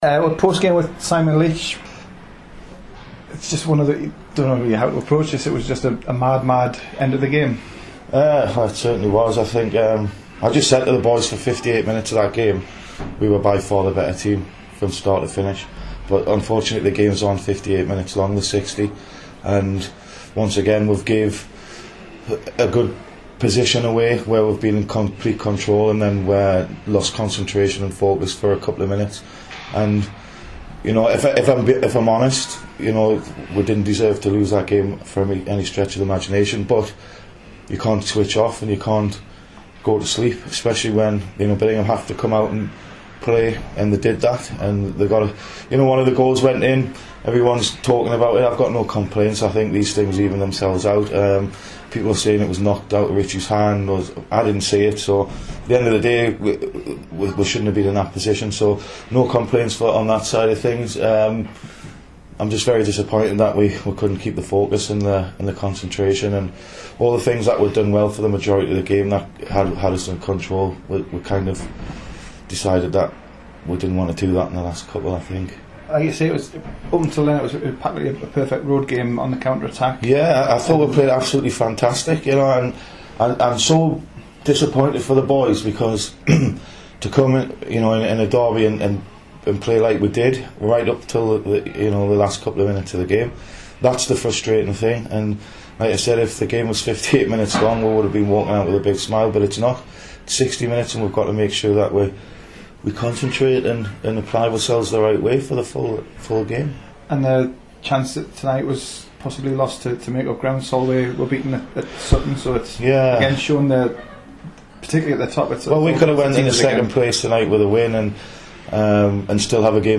Hear his frank post-game interview, moments after the final hooter.